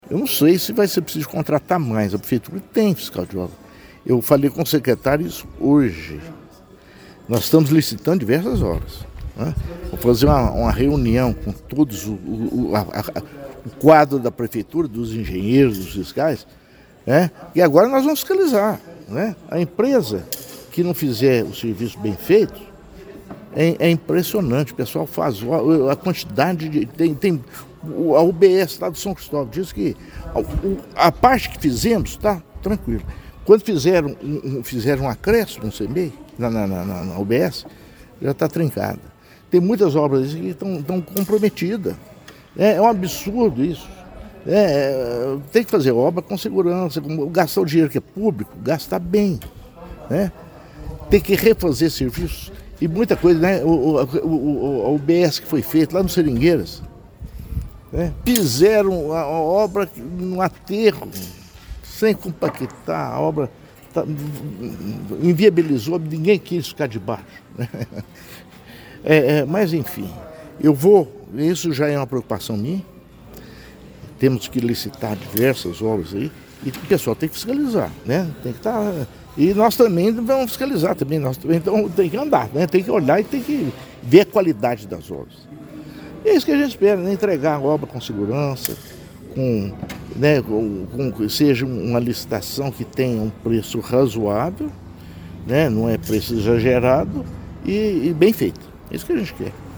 Diante de relatos de trincas e avarias em outras construções da cidade, o prefeito reforçou que a fiscalização será intensificada para garantir a segurança dos alunos e o uso eficiente do dinheiro público.